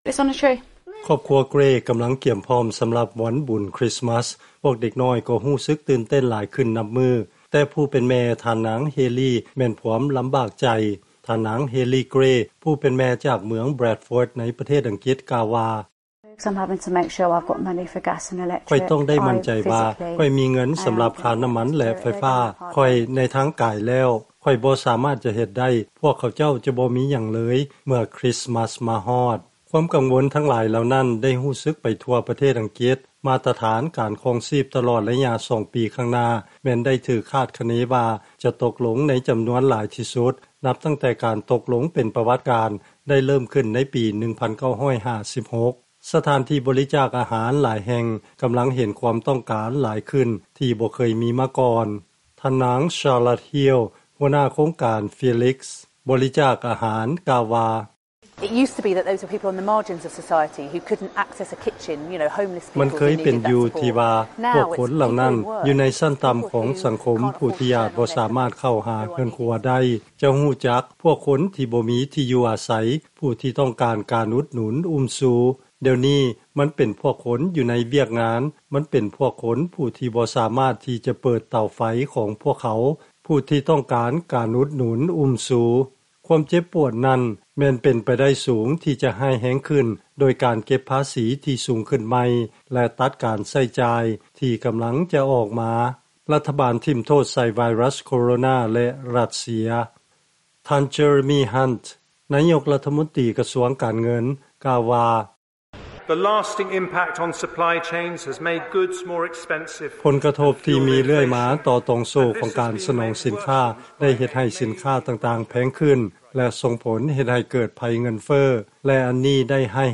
ຟັງລາຍງານ ເສດຖະກິດ ອັງກິດ ຕົກຕ່ຳລົງຫຼາຍທີ່ສຸດ ໃນກຸ່ມ G-7 ຂະນະທີ່ ເບຣກສິຕ ແລະຄວາມວຸ້ນວາຍທາງການເມືອງ ໄດ້ສົ່ງຜົນກະທົບ